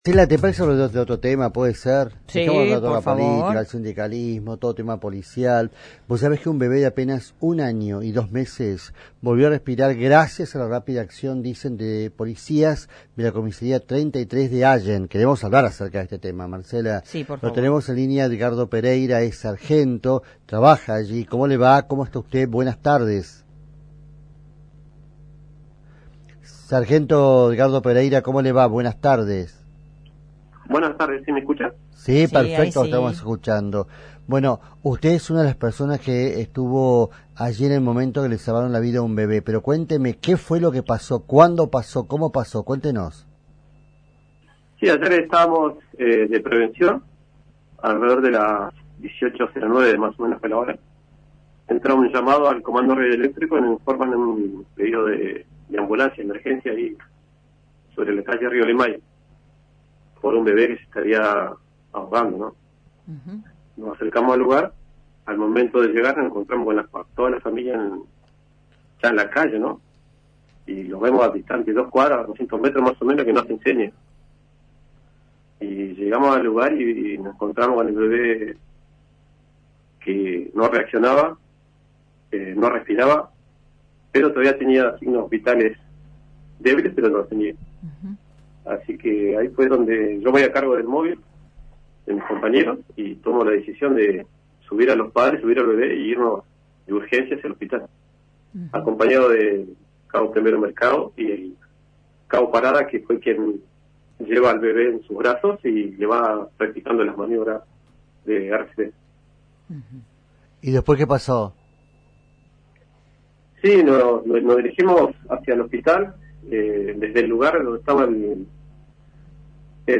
Escuchá la entrevista completa en nuestro audio adjunto.